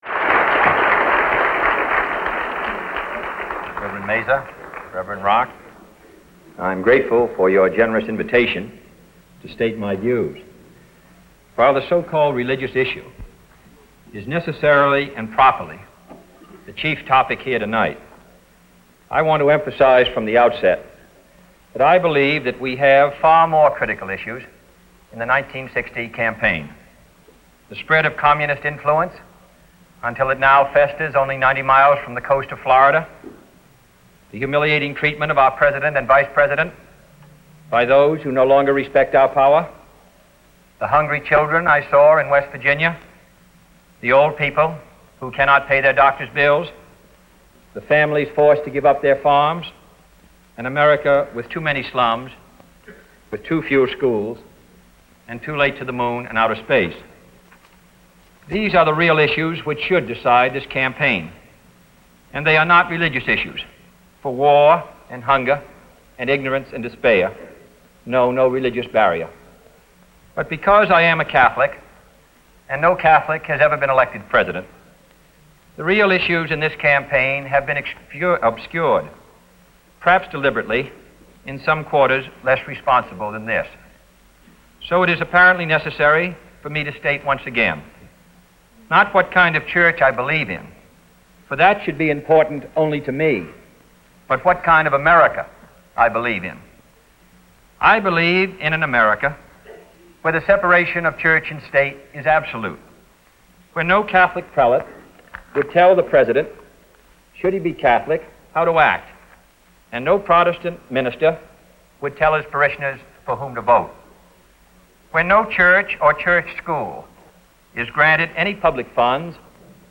Address to the Greater Houston Ministerial Association by John F. Kennedy on Free Audio Download
JohnFKennedyAddresstotheGreaterHoustonMinisterialAssociation.mp3